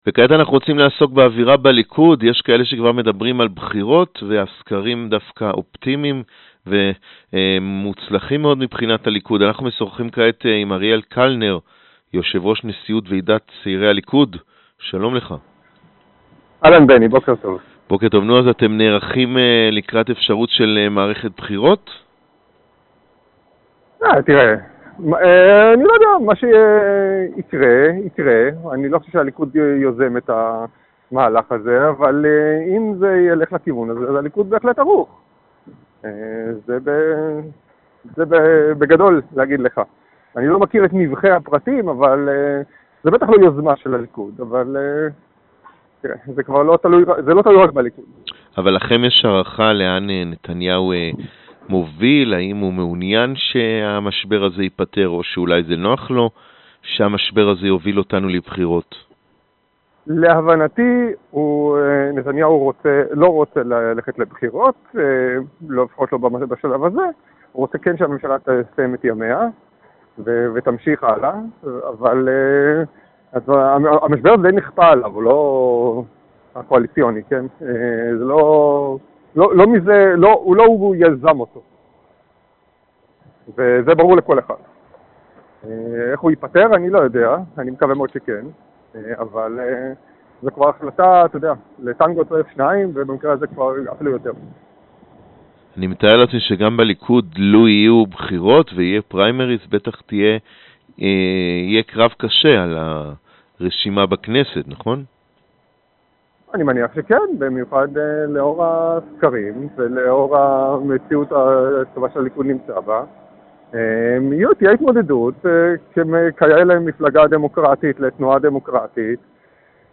Aриэль Келнер, председатель президиума конференции «Молодого Ликуда», говорит корреспонденту 7 канала, что, по его мнению, премьер-министр Биньямин Нетаньяху не пойдет на досрочные выборы.